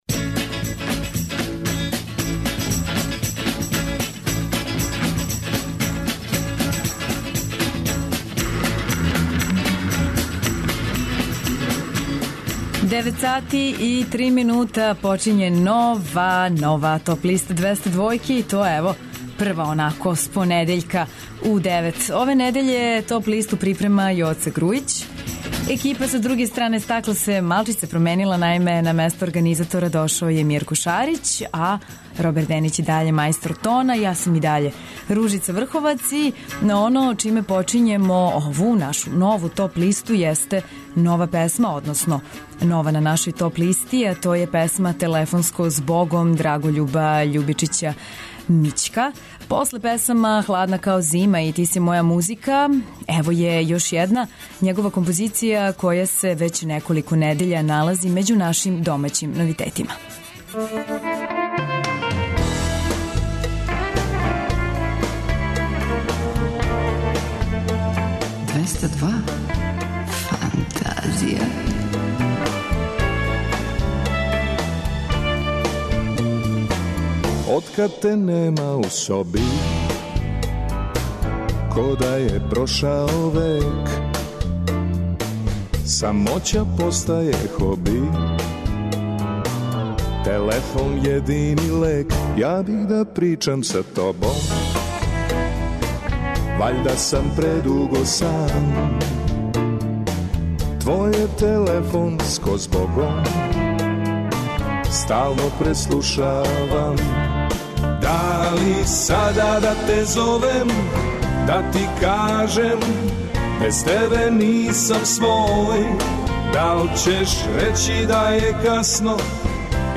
Најавићемо актуелне концерте у овом месецу, подсетићемо се шта се битно десило у историји музике у периоду од 12. до 16. октобра. Ту су и неизбежне подлисте лектире, обрада, домаћег и страног рока, филмске и инструменталне музике, попа, етно музике, блуза и џеза, као и класичне музике.